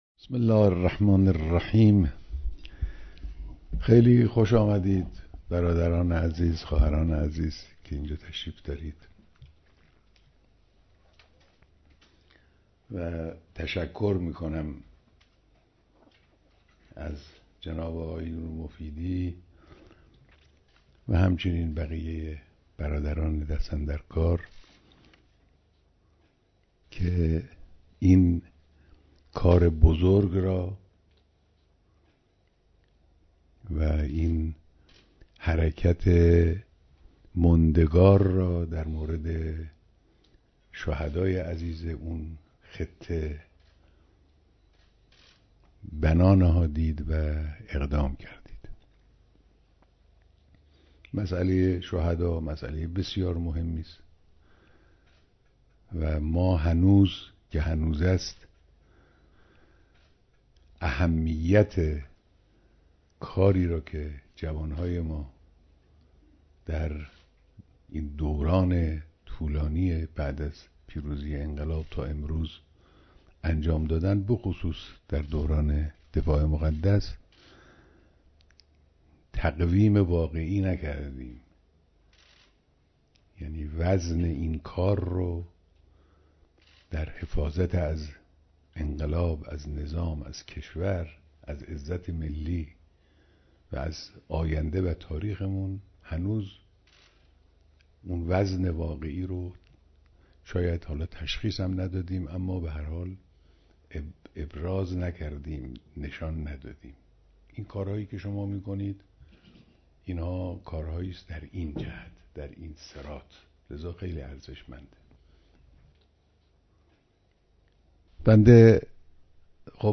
بیانات در دیدار اعضای ستاد بزرگداشت چهار هزار شهید استان گلستان